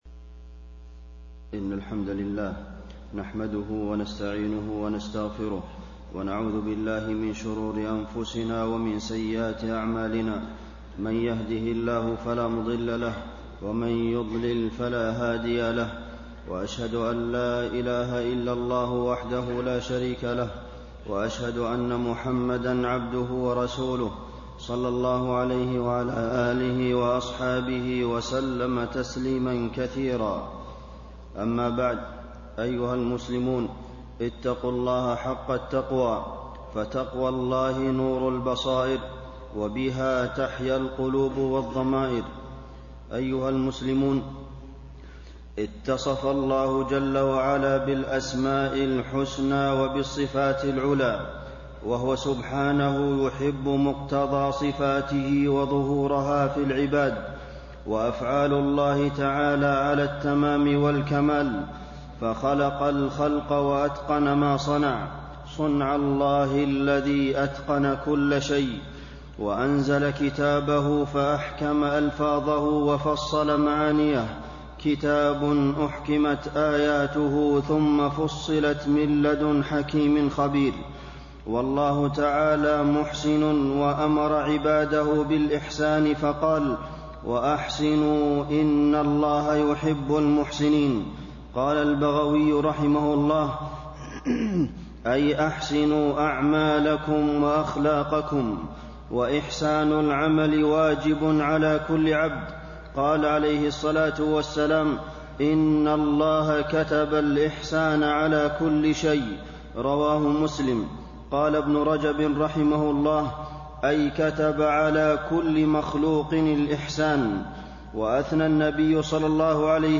تاريخ النشر ٢٩ صفر ١٤٣٤ هـ المكان: المسجد النبوي الشيخ: فضيلة الشيخ د. عبدالمحسن بن محمد القاسم فضيلة الشيخ د. عبدالمحسن بن محمد القاسم طلب المعالي بالأعمال الصالحة